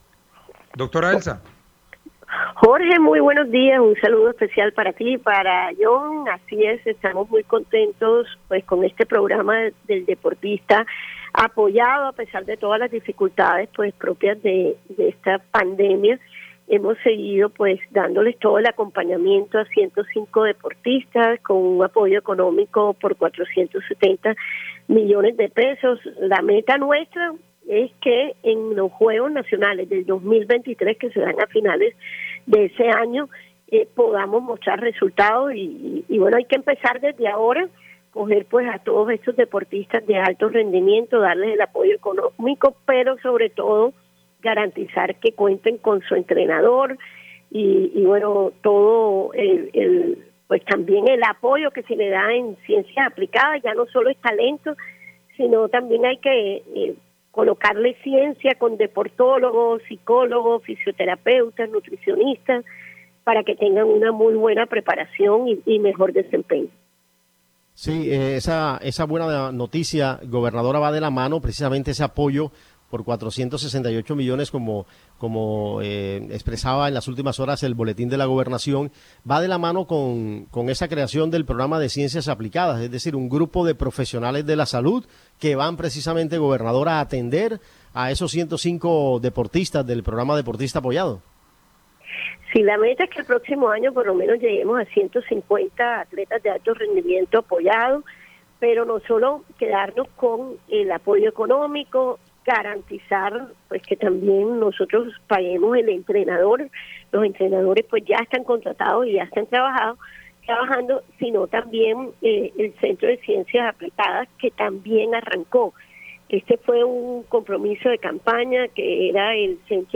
La gobernadora del Atlántico, Elsa Noguera, pidió a los habitantes de Repelón que voten a conciencia para la Alcaldía de ese municipio.